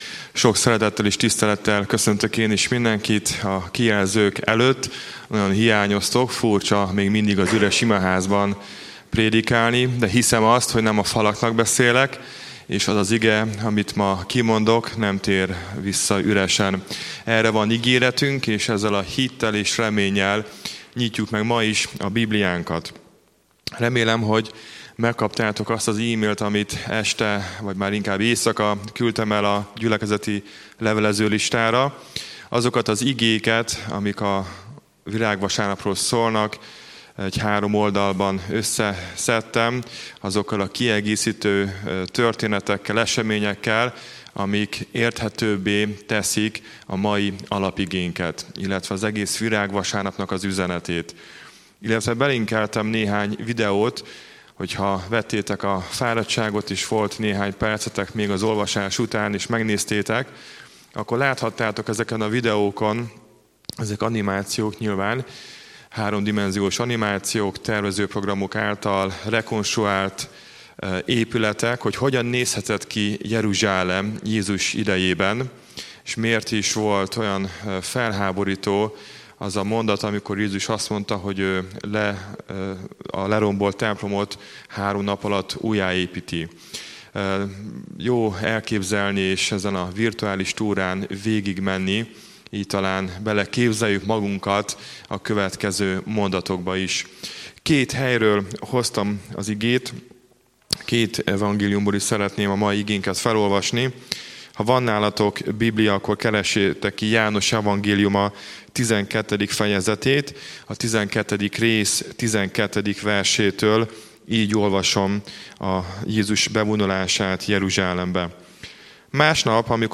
Székesfehérvári Baptista Gyülekezet Igehirdetések